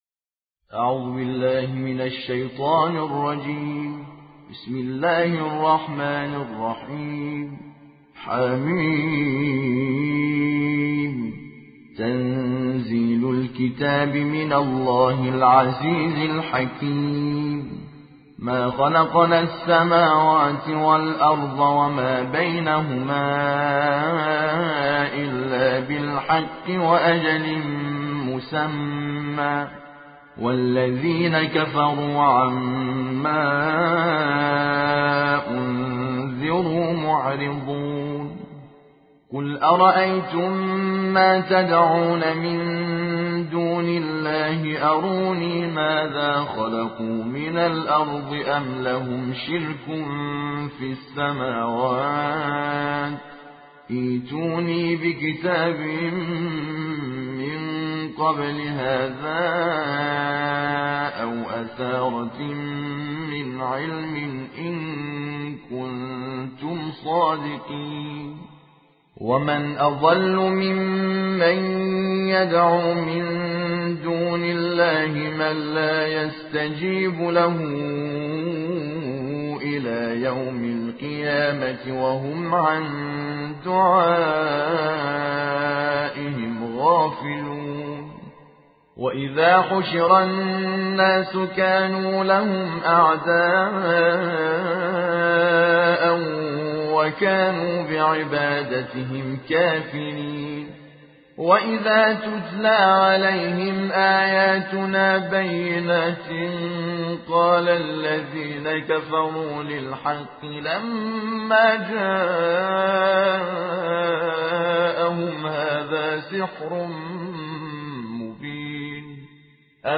ترتیل جزء سی ام - حامد شاکر نژاد | پایگاه تخصصی مسجد